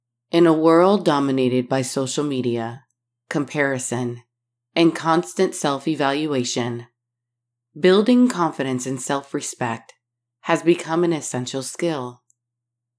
Actually, it doesn’t pass ACX.
And there’s still some “room” bounce in there.
Here is a sample with ACX specifications